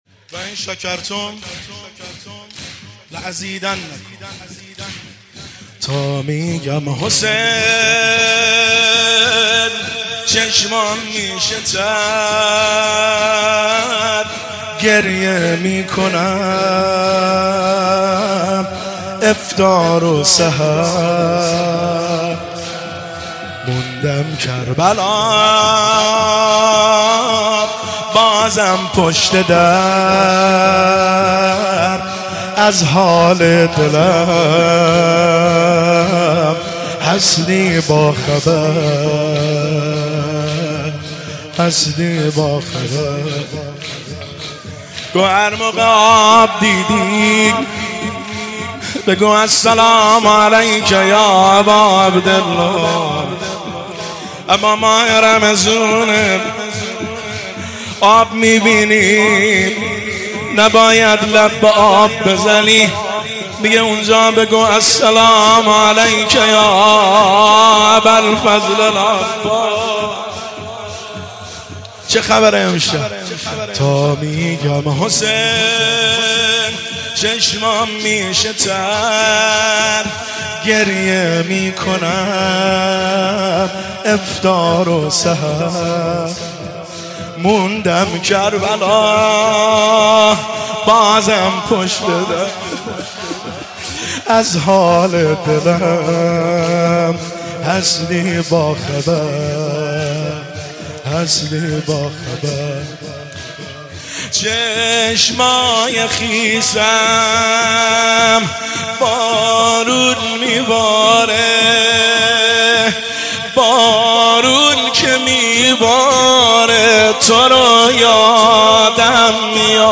زمینه شب 20 رمضان المبارک 1403